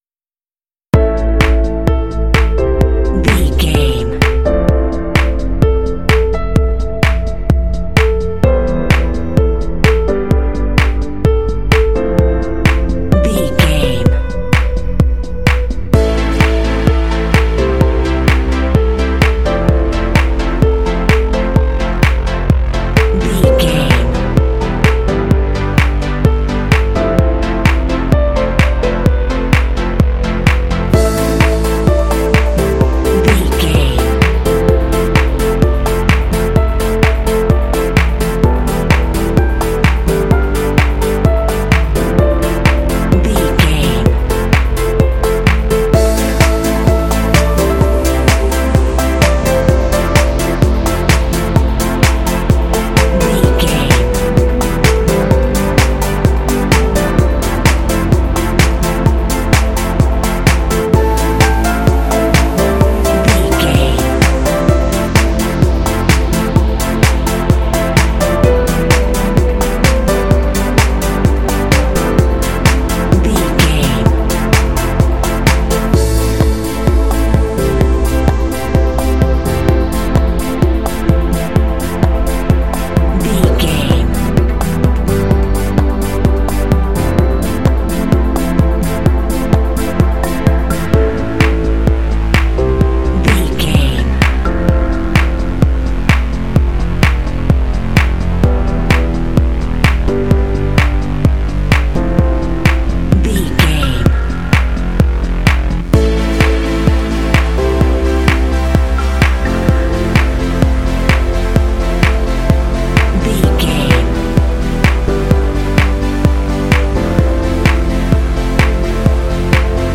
Uplifting
Aeolian/Minor
cool
smooth
piano
drums
synthesiser
pop